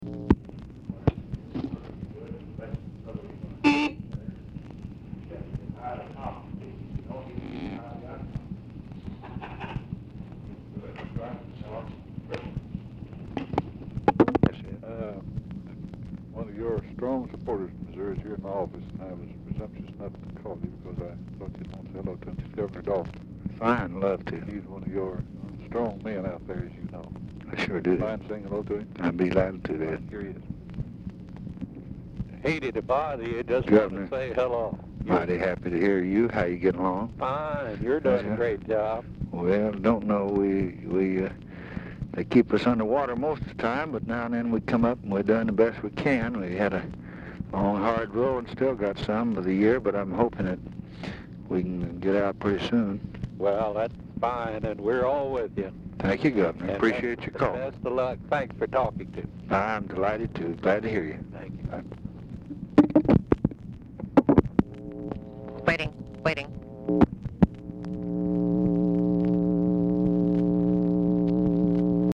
Telephone conversation
BRIEF OFFICE CONVERSATION PRECEDES CALL
Format Dictation belt